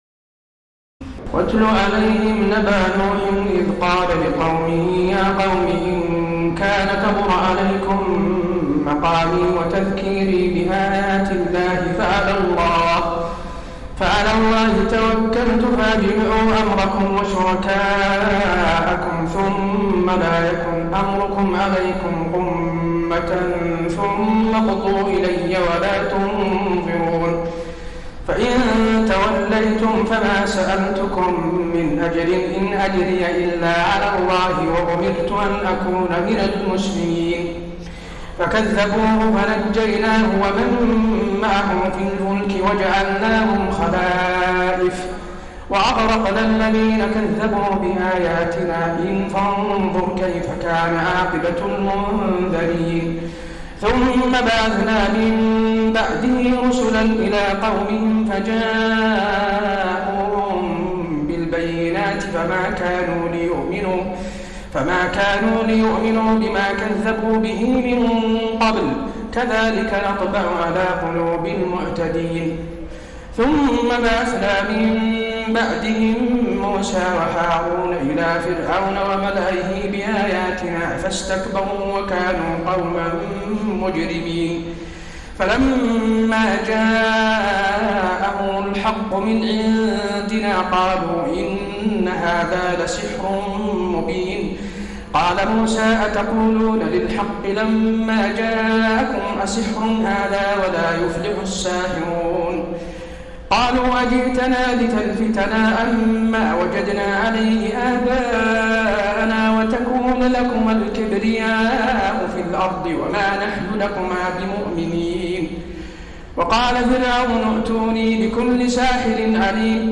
تراويح الليلة الحادية عشر رمضان 1433هـ من سورتي يونس (71-109) و هود (1-60) Taraweeh 11 st night Ramadan 1433H from Surah Yunus and Hud > تراويح الحرم النبوي عام 1433 🕌 > التراويح - تلاوات الحرمين